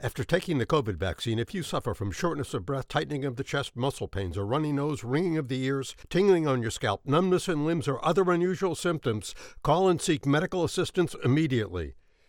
Voiceover Artist,
Sex: Male
Ages Performed: Middle Age, Senior,
Mac Book Pro-Garageband Fast Track Pro-USB audio interface RODE NT1A-P Condenser Microphone Headphones-Sony 7506
Professional Male Voiceover Talent Neutral North American English Resonant, smooth, warm, creditable and trustworthy for company spokesperson, training, political commercials and narrations Registered Pharmacist-Very familiar with medical terminology